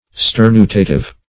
Sternutative \Ster*nu"ta*tive\, a. Having the quality of provoking to sneeze.